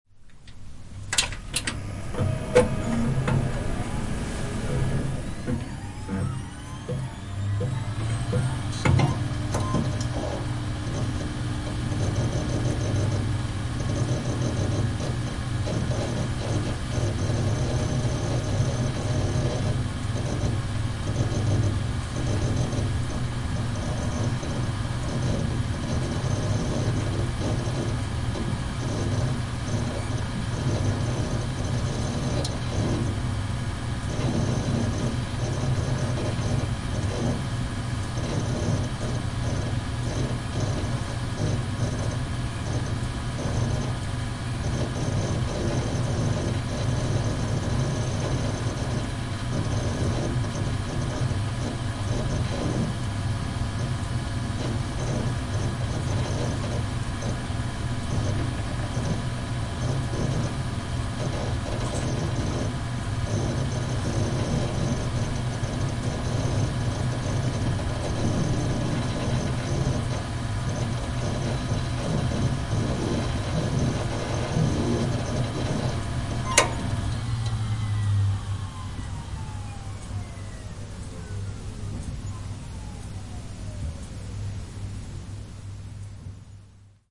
Download Computer sound effect for free.
Computer